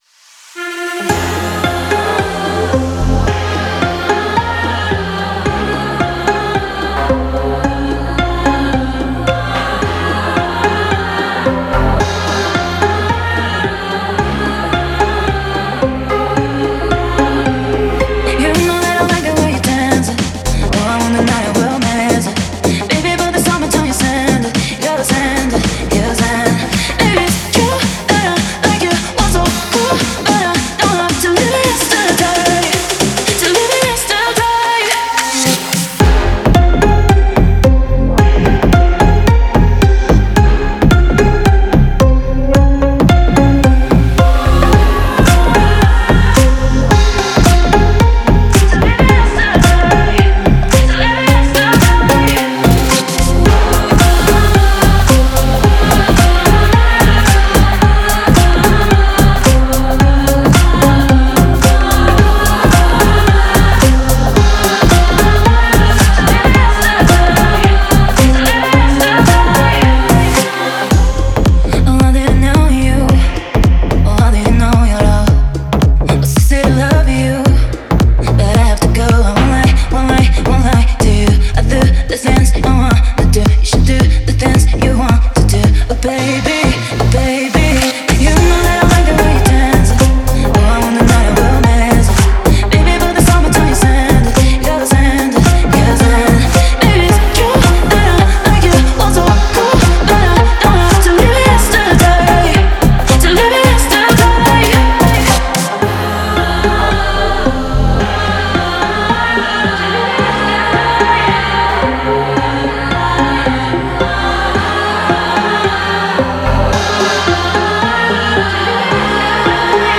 это нежная и меланхоличная композиция в жанре инди-поп.